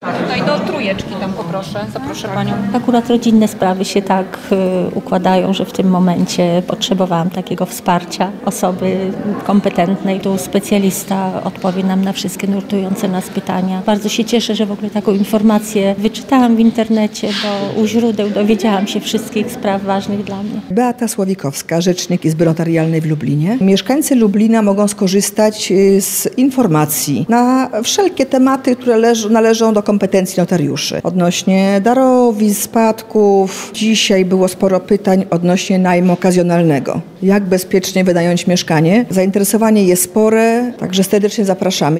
– Akurat rodzinne sprawy układają się tak, że potrzebowałam wsparcia osoby kompetentnej – mówi jedna z mieszkanek.